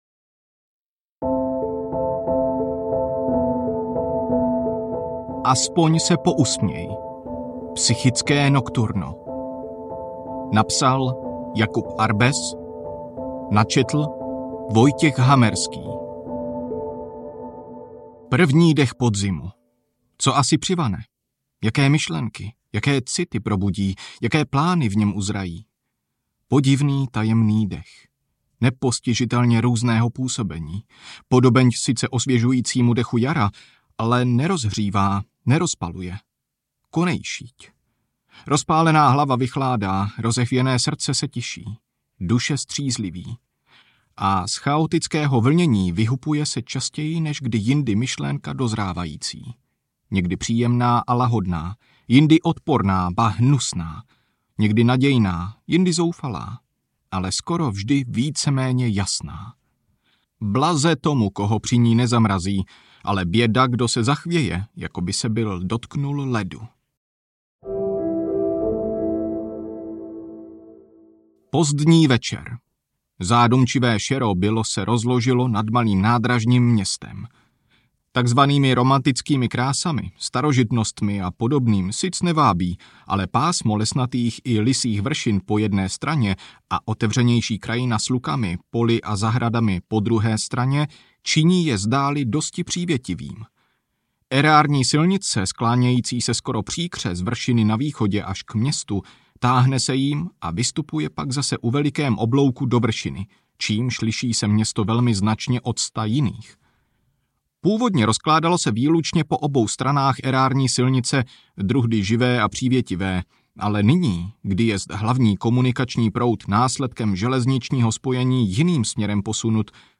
Aspoň se pousměj! audiokniha
Ukázka z knihy